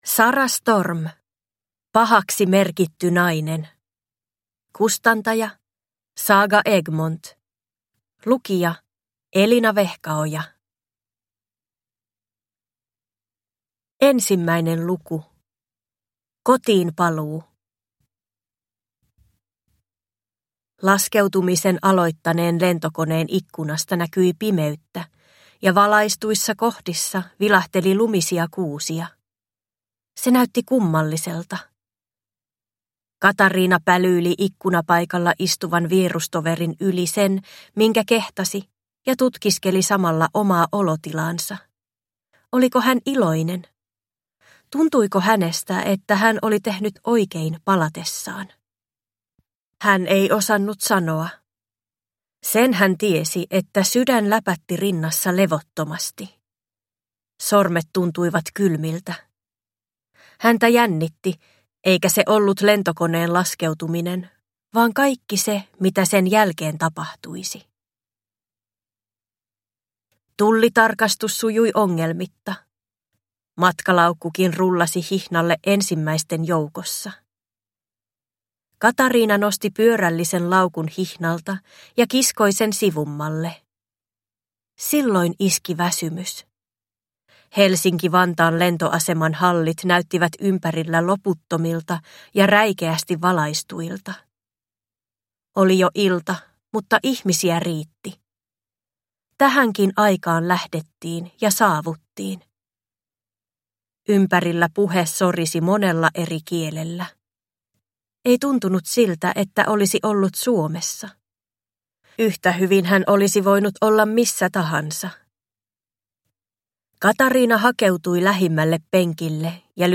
Pahaksi merkitty nainen – Ljudbok